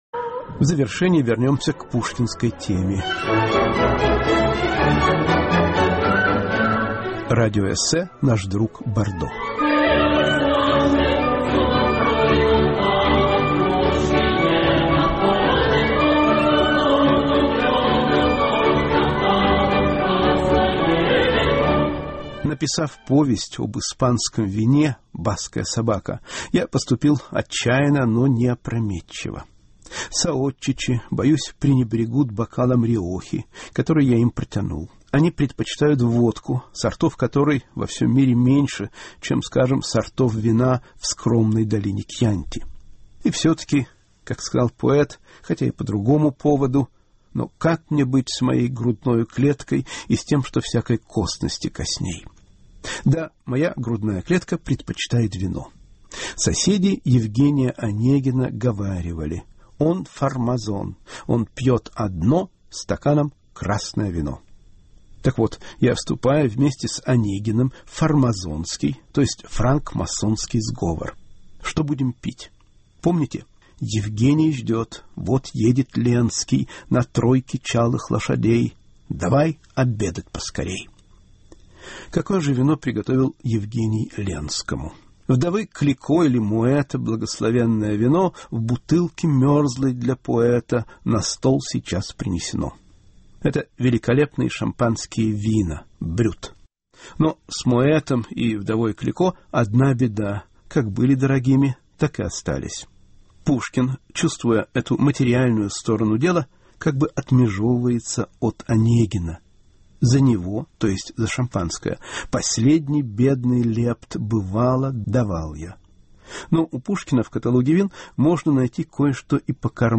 радиоэссе "Наш друг бордо"